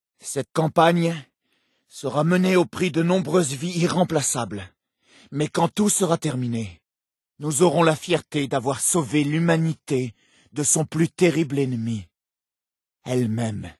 Catégorie:Dialogues audio de Fallout 4